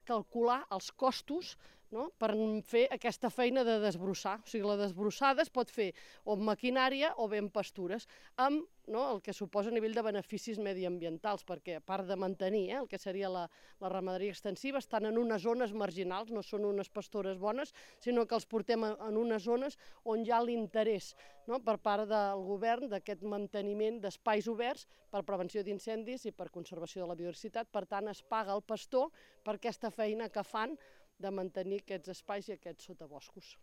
La voluntat del pla pilot és calcular el cost de netejar el bosc perquè el Govern contracti pastors i els ofereixi desenvolupar aquesta tasca al territori, substituint així les màquines. Ho explica la directora general d’Agricultura i Ramaderia a l’Agència Catalana de Notícies (ACN), Elisenda Guillaumes.